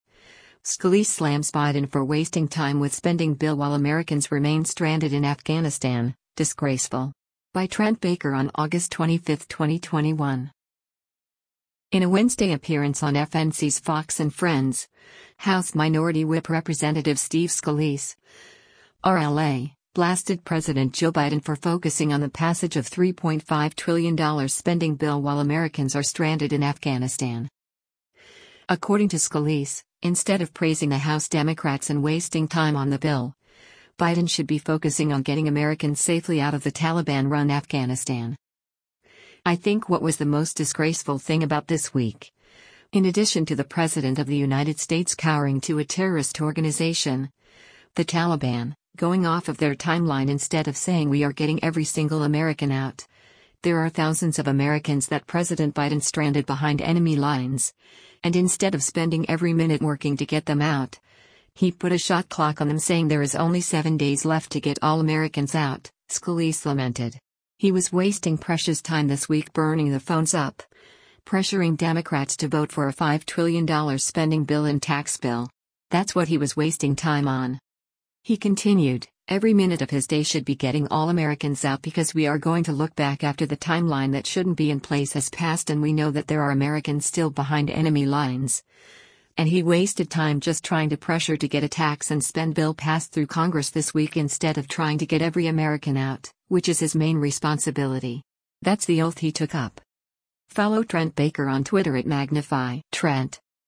In a Wednesday appearance on FNC’s “Fox & Friends,” House Minority Whip Rep. Steve Scalise (R-LA) blasted President Joe Biden for focusing on the passage of $3.5 trillion spending bill while Americans are “stranded” in Afghanistan.